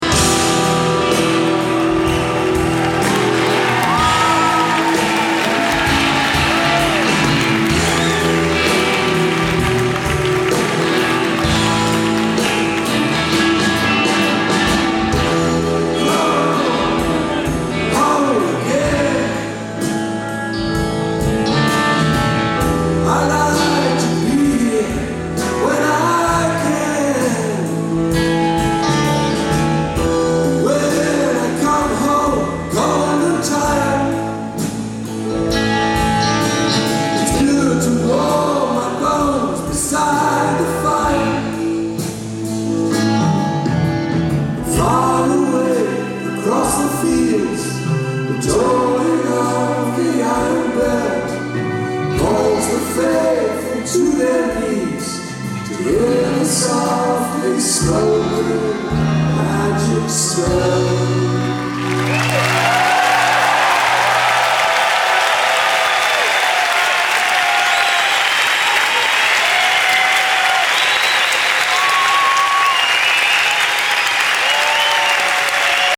Earl's Court.